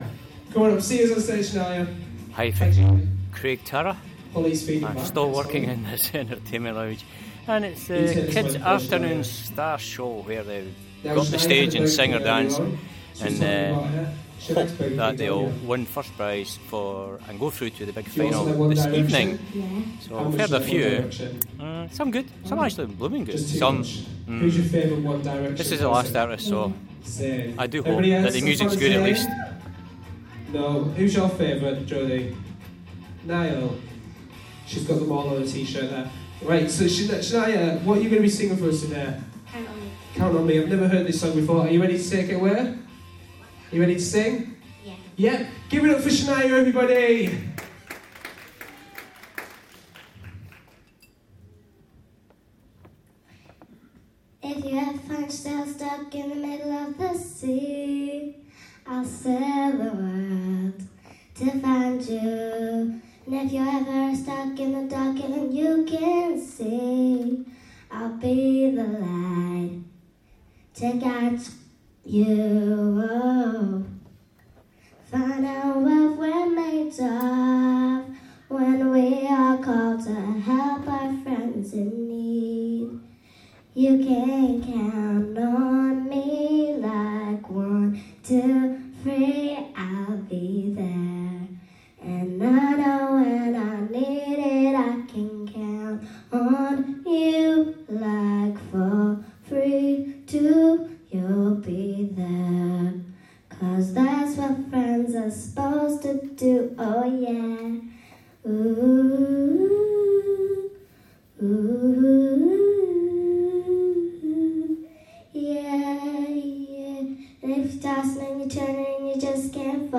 Craig Tara - Final soundscape.